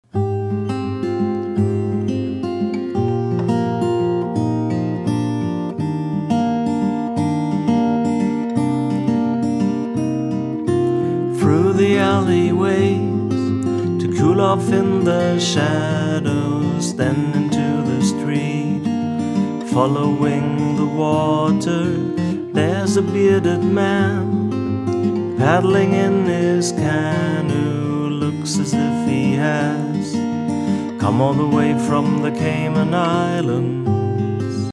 Großmembranmikro für weichen Bariton
Hallo, ich habe mit dem Gefell nochmal eine Mono-Testaufnahme Git/Voc über den Summit 2BA gemacht, ca 1m Abstand. Man hört ja wirklich jedes Schmatzen.